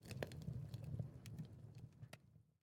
fire_crackle1.ogg